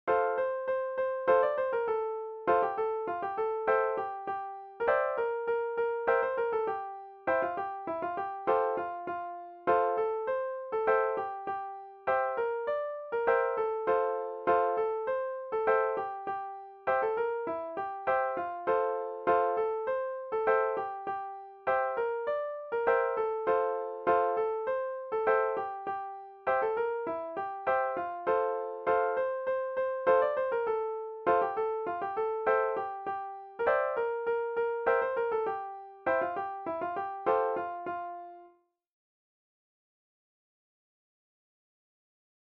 Deense volksmuziek